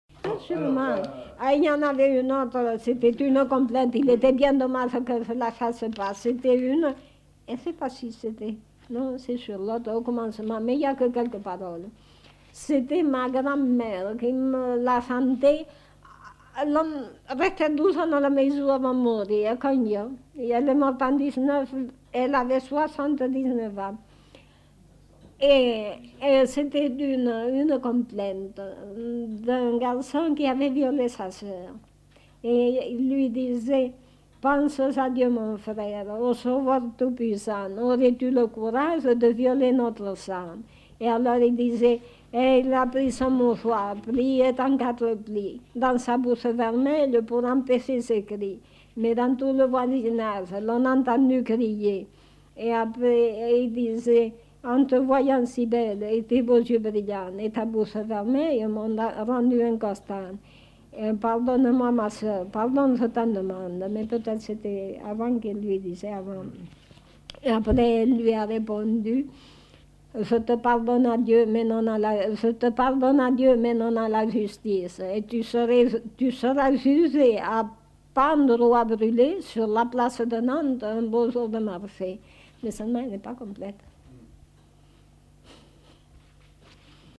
Aire culturelle : Périgord
Lieu : La Chapelle-Aubareil
Genre : chant
Effectif : 1
Type de voix : voix de femme
Production du son : lu